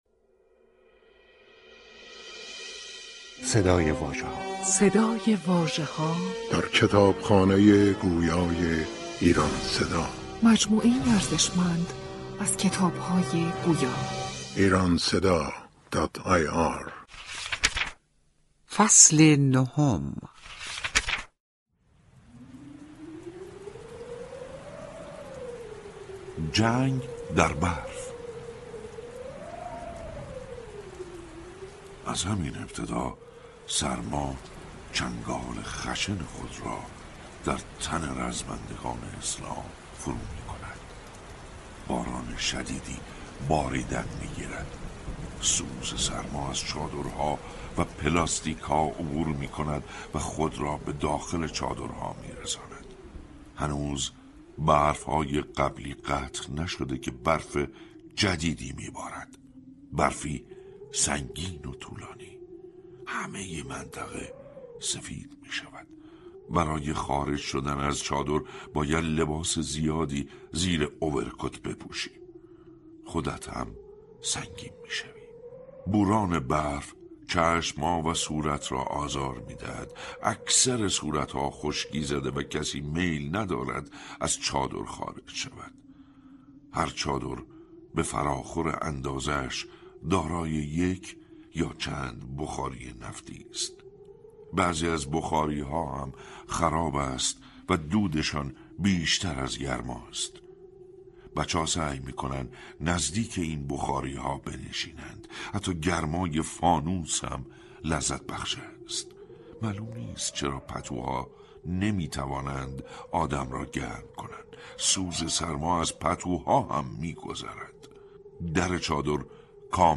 کتاب گویای «سینای شلمچه» تهیه و بر روی پایگاه کتاب گویای ایران صدا در دسترس علاقه‌مندان قرارگرفته است.